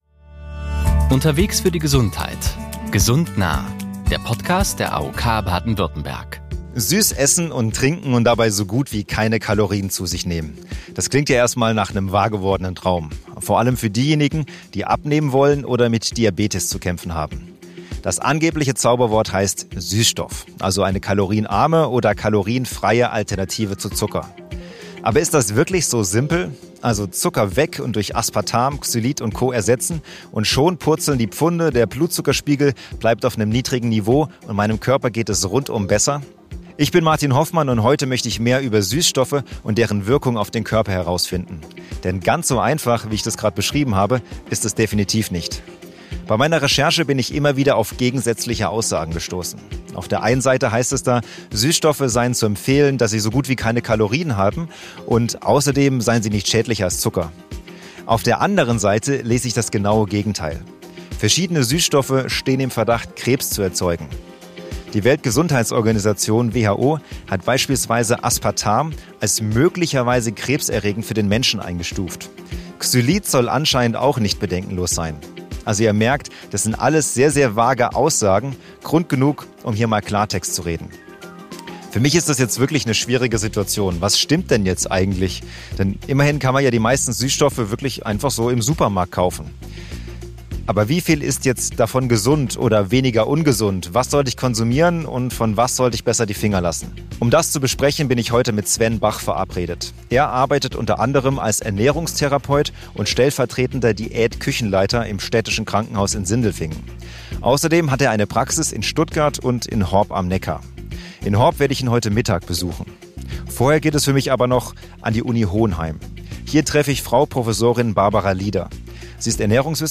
Zwei Expert/-innen klären auf.